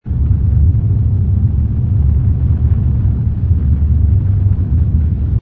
rumble_cruiser.wav